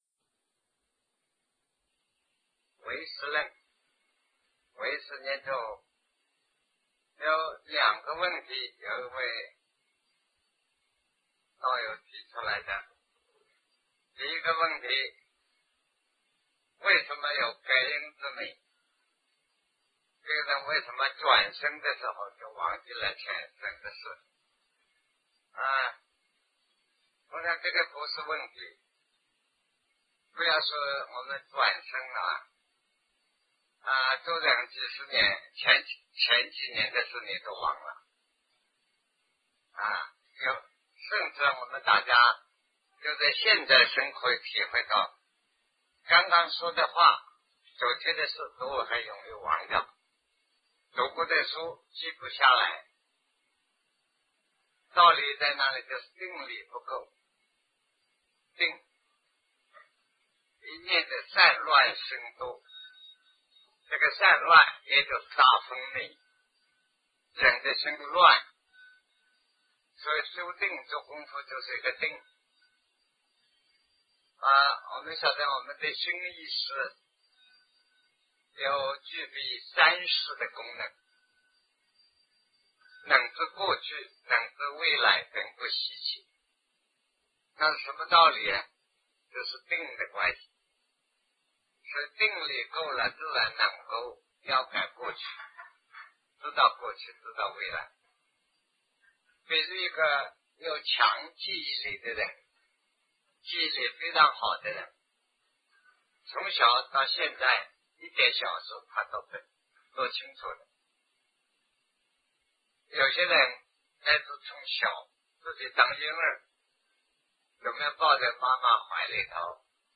隔阴之谜 南师讲唯识与中观（1980代初于台湾015(上)